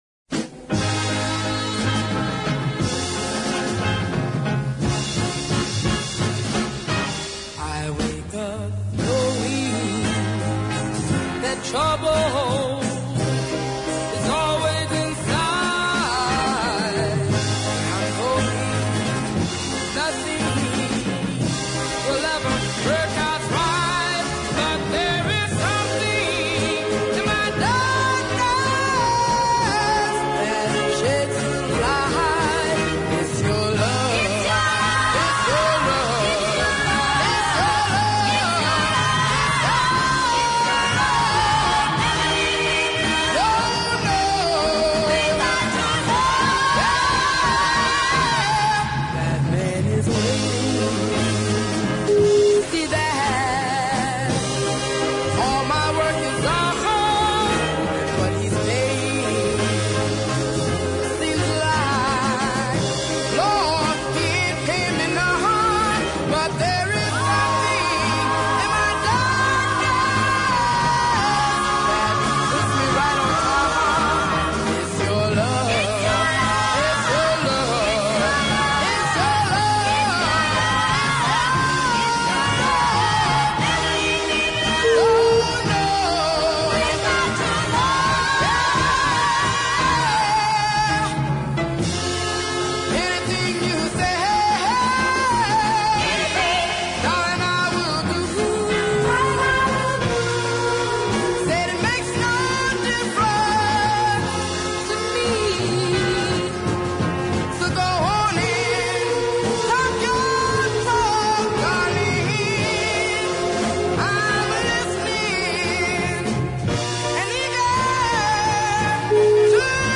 hard hitting track
Her vocal exudes authority and power here
the big arrangement and production